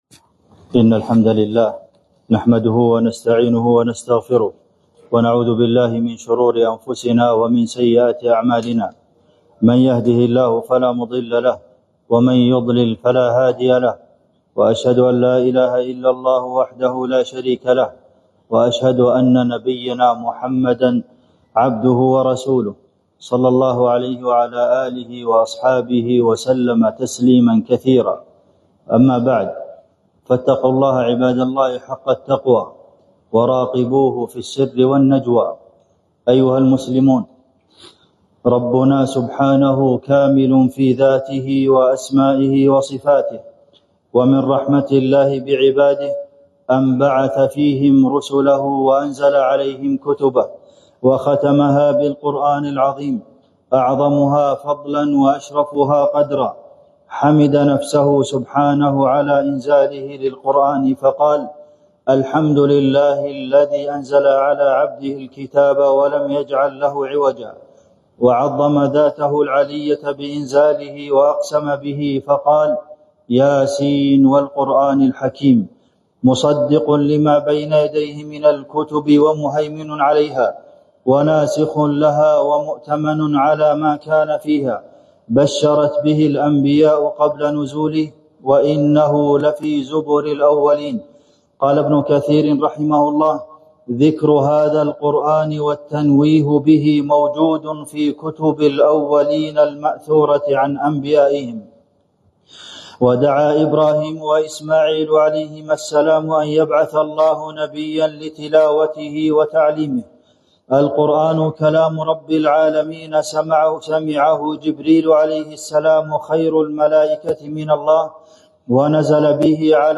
عظمة القرآن | خطبة الجمعة ٢٤ ربيع الأول ١٤٤٦ من المسجد الجامع بلوساكا، زامبيا > زيارة الشيخ عبدالمحسن القاسم لـ جمهورية زامبيا > تلاوات و جهود الشيخ عبدالمحسن القاسم > المزيد - تلاوات الحرمين